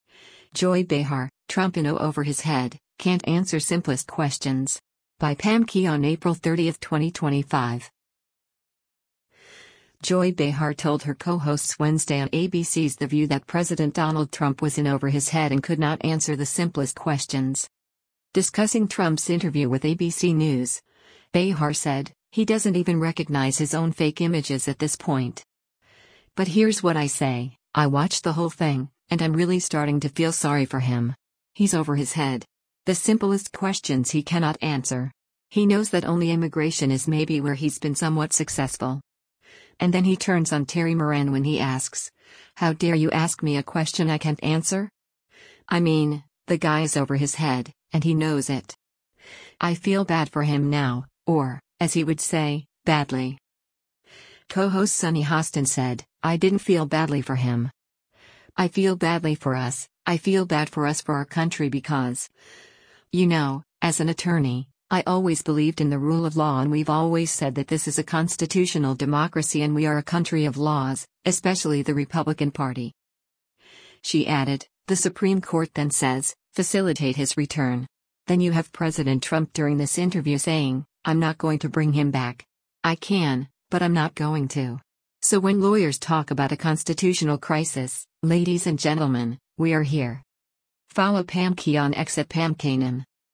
Joy Behar told her co-hosts Wednesday on ABC’s “The View” that President Donald Trump was in “over his head” and could not answer the “simplest questions.”